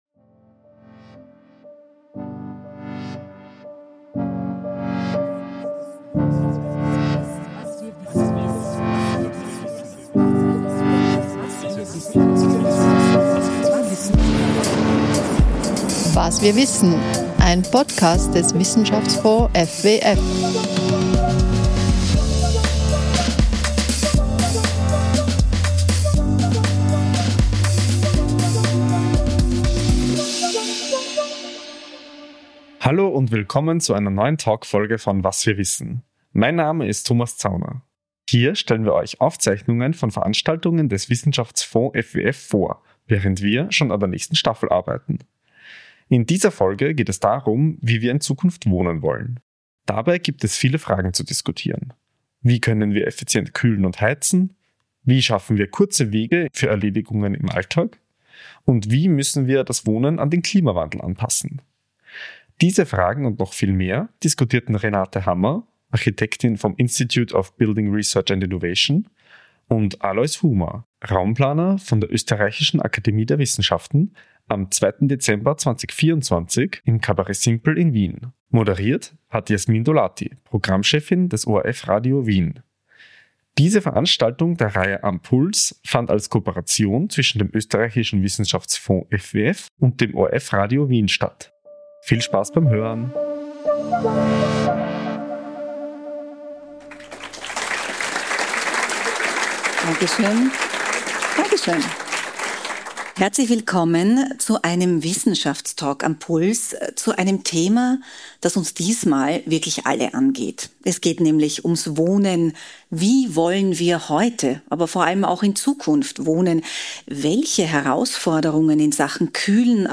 Diese Veranstaltung fand im Kabarett Simpl in Wien in Kooperation mit dem ORF-Radio Wien statt.